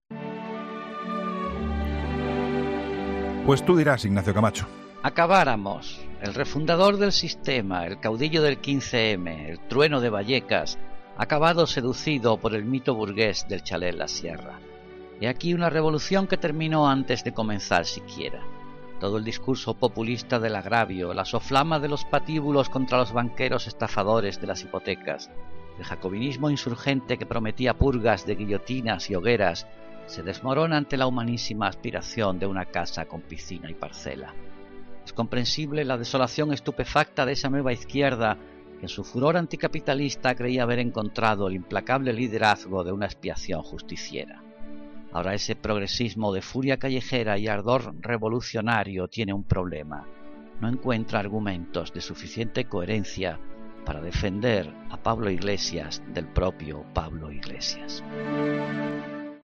Ignacio Camacho habla en 'La Linterna' de las contradicciones de Iglesias tras comprarse un chalé de 600.000 euros